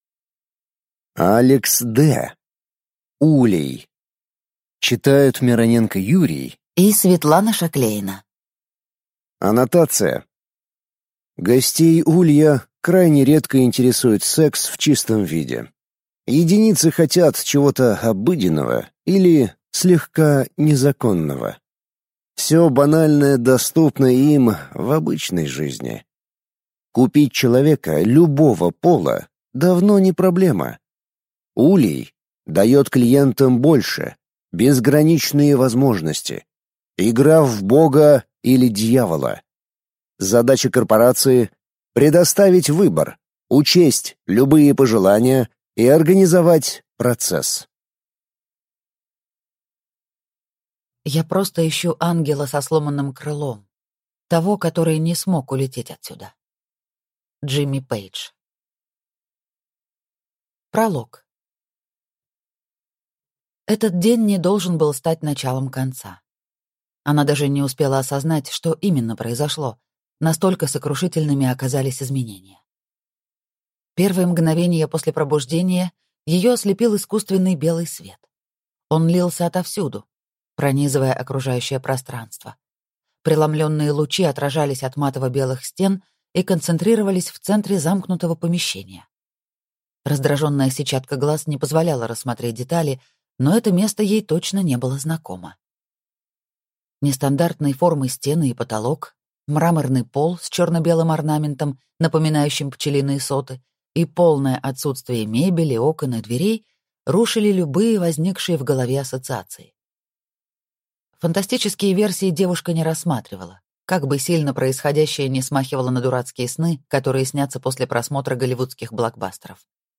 Аудиокнига Улей | Библиотека аудиокниг
Aудиокнига Улей Автор Алекс Д